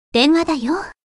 女性の声で「電話だよー！」と話す着信音。